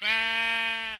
sheep3.ogg